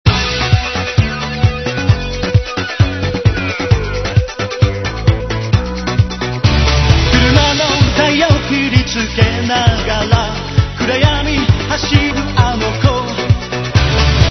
変な音楽
つぎはぎ音楽のＭＰ３であるゆー事ですねぇい(^^;